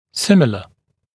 [‘sɪmɪlə][‘симилэ]подобный, похожий, сходный